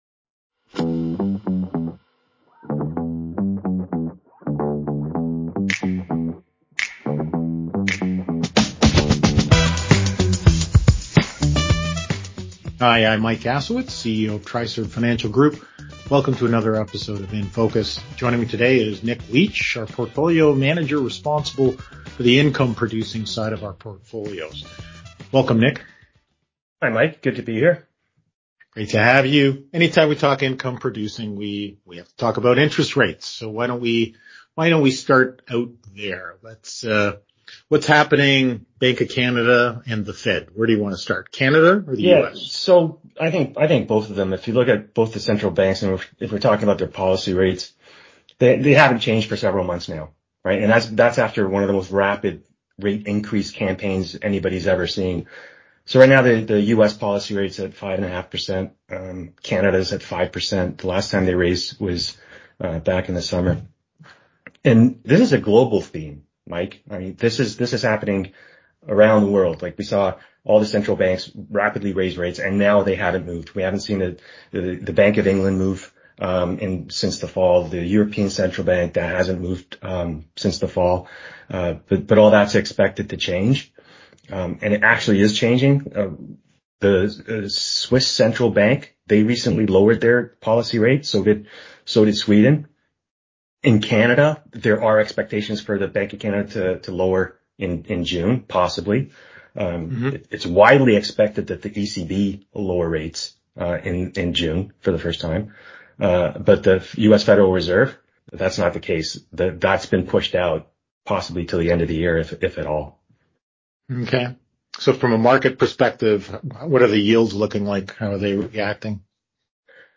Investment Management | Podcast